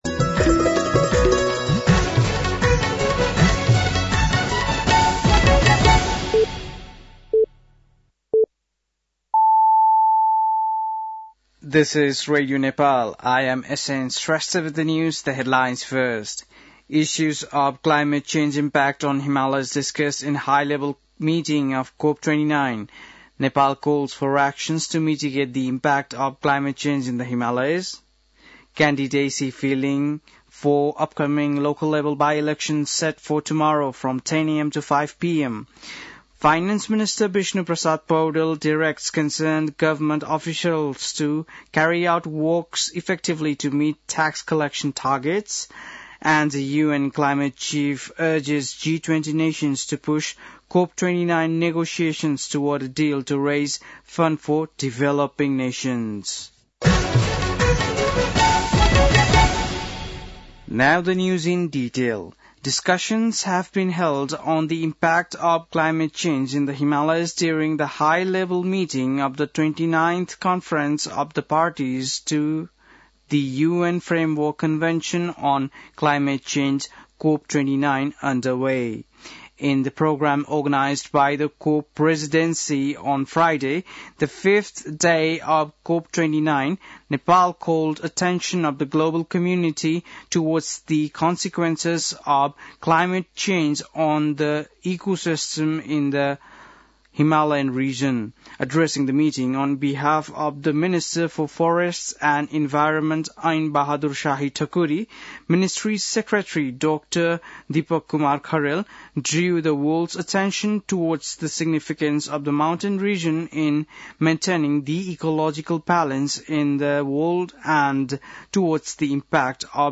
बेलुकी ८ बजेको अङ्ग्रेजी समाचार : २ मंसिर , २०८१
8-PM-English-News-8-1.mp3